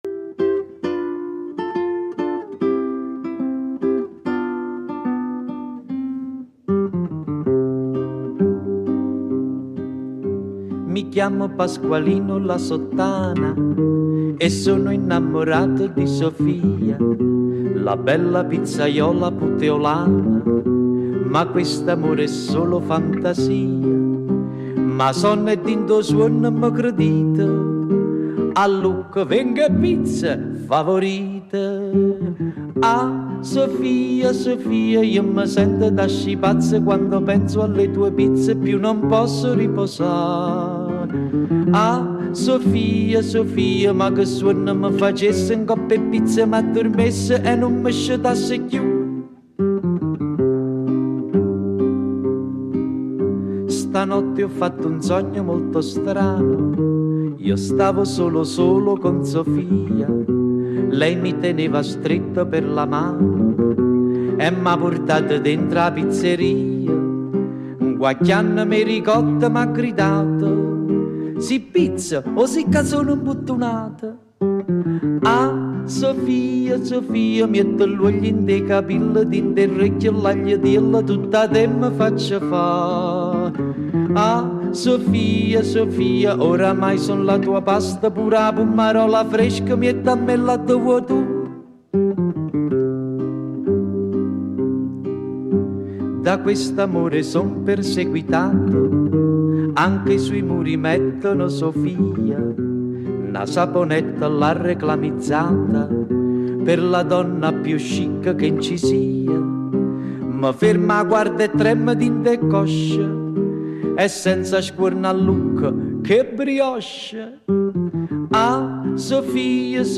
Красивая, ритмичная!